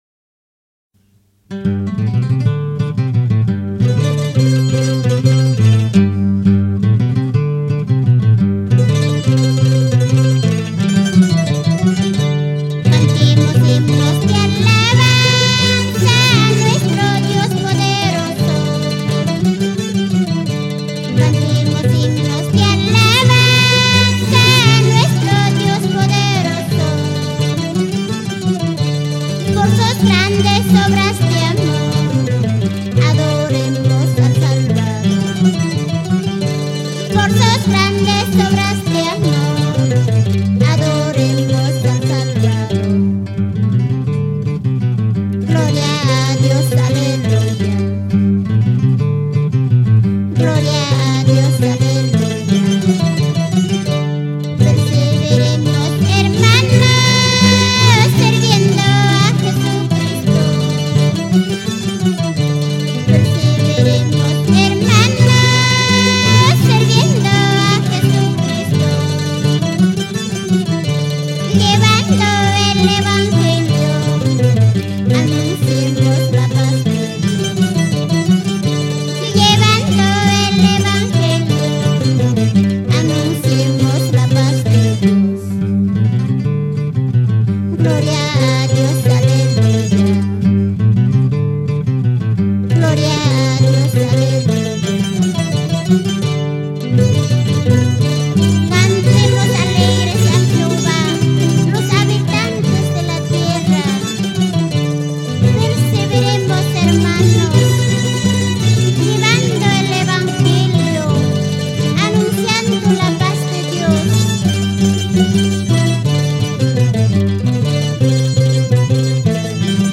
Guitarras:
Mandolinas: